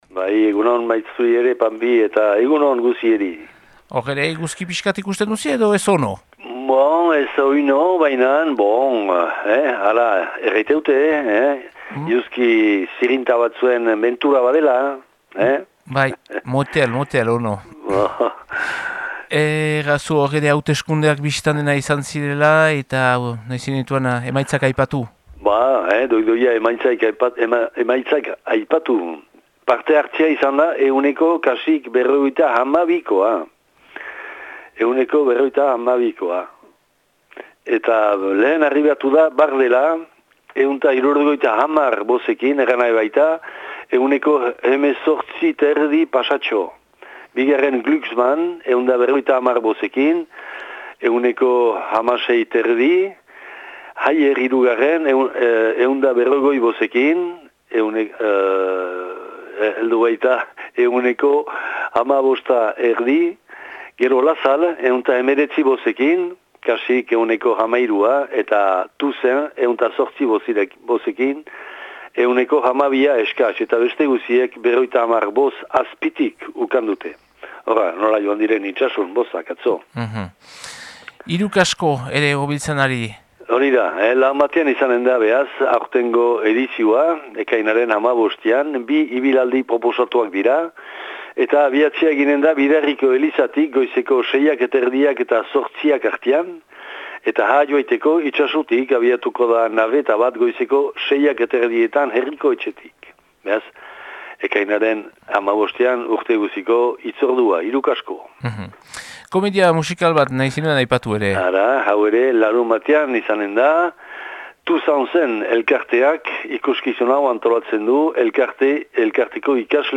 Ekainaren 10eko Itsasuko berriak